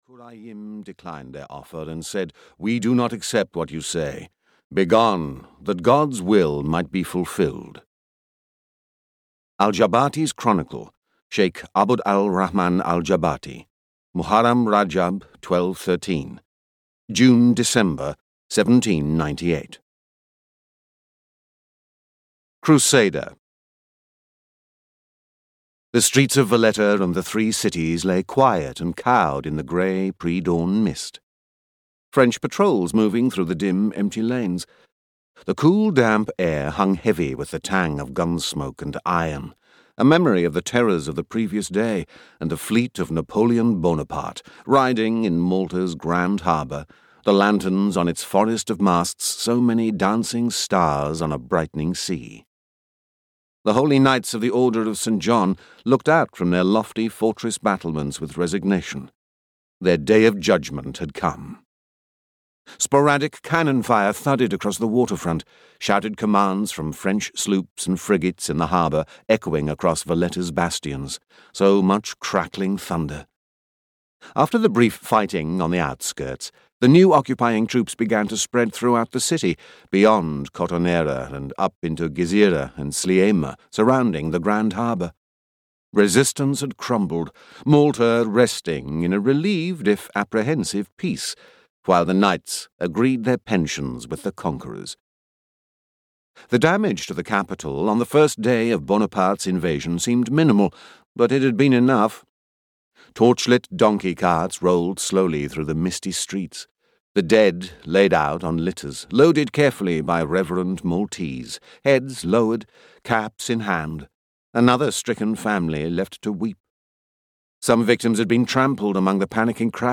Lords of the Nile (EN) audiokniha
Ukázka z knihy